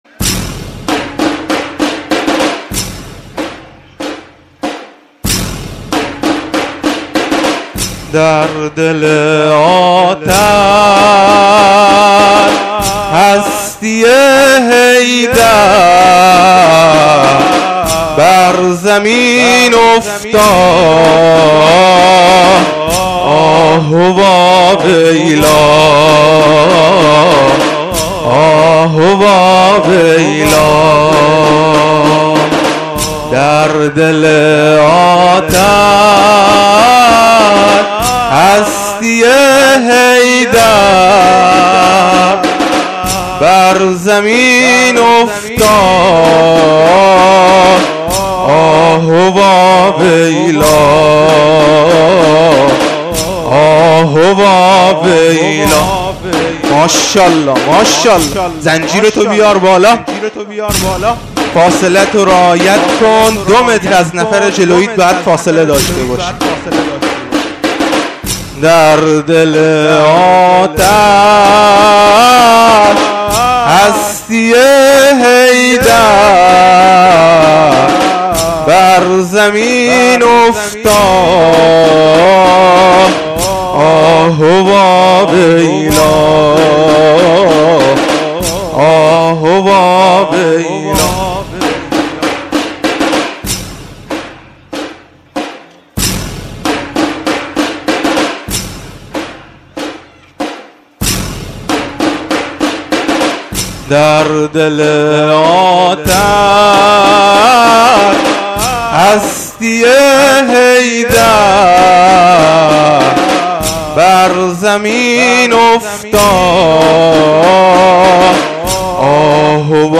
زنجیرزنی ، شب شهادت حضرت زهرا(س) ، هیئت زنجیرزنان شهدای مسجدصالح